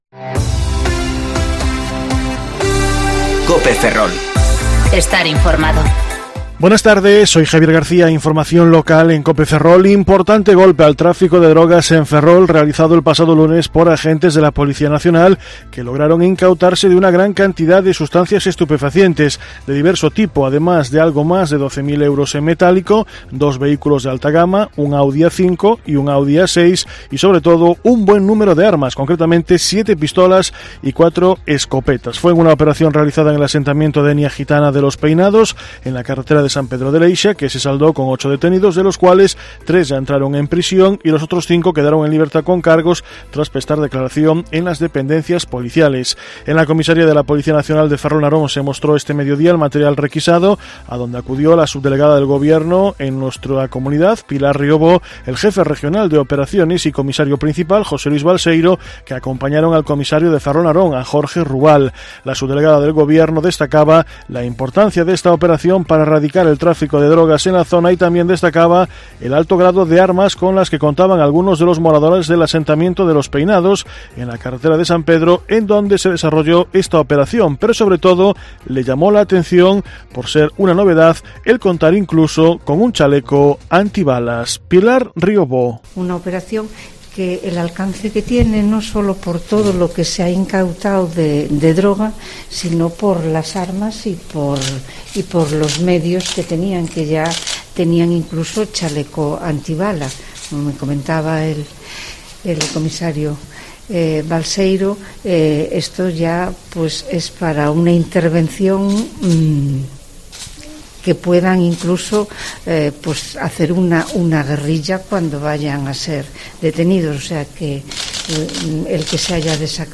Informativo Mediodía Cope Ferrol 24/10/2019 (De 14.20 a 14.30 horas)